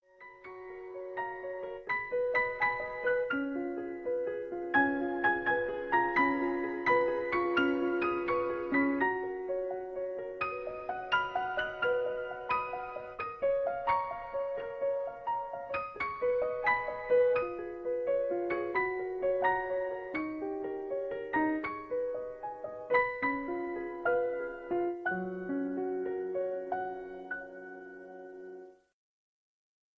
Einige Variationen zum berühmten Volkslied